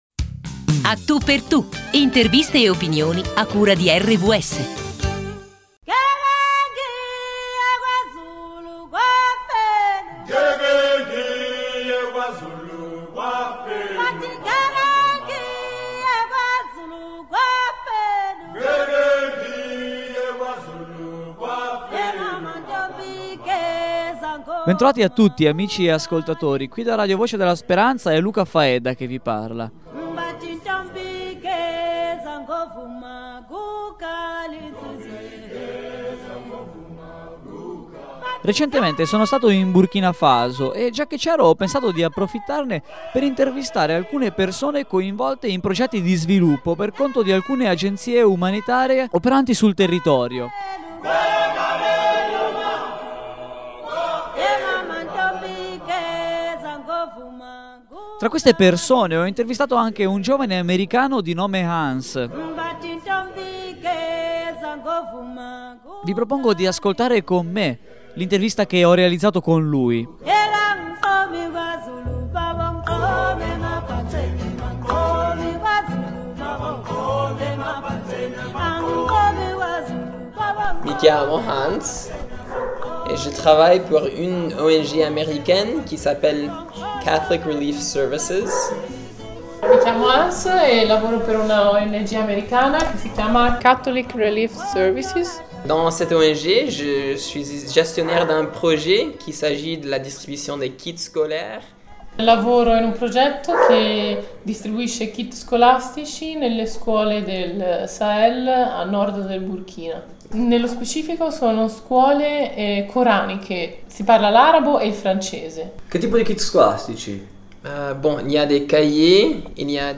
Radio Podcast